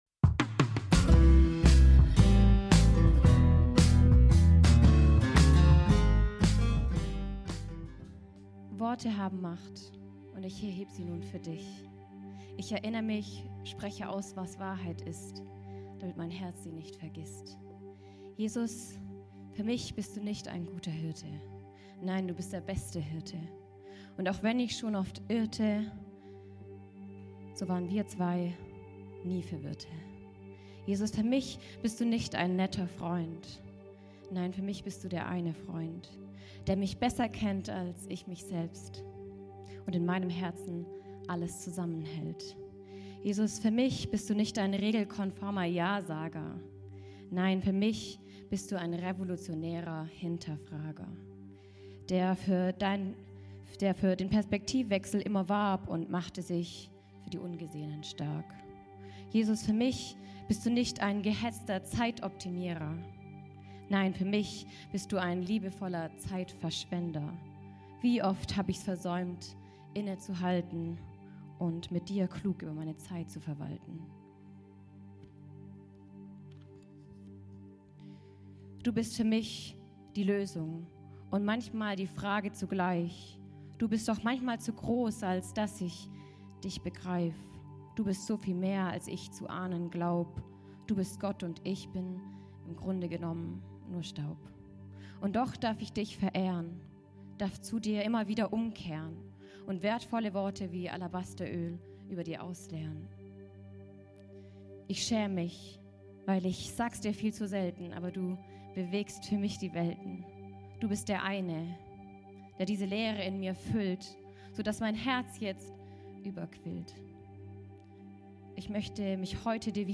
alle Predigten